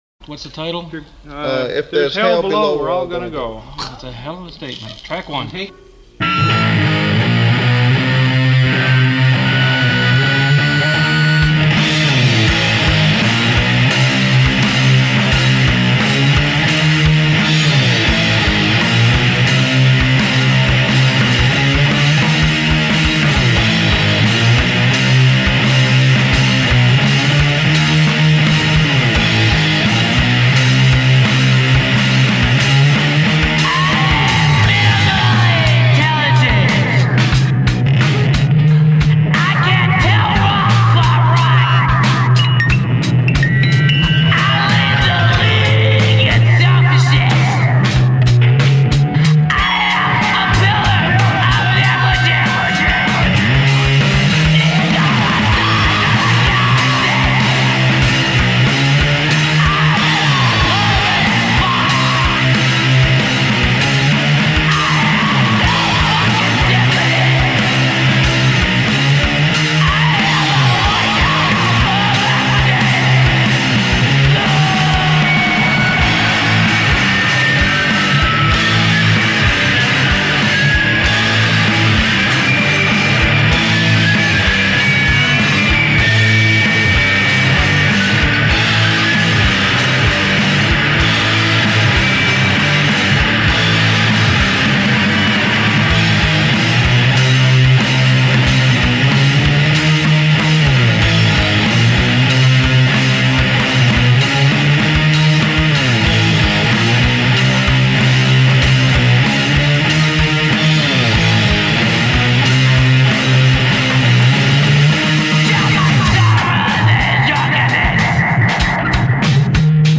hardcore n roll
grunge-rock
garage fuzz beat
leftfield pop
Ethio jazz funk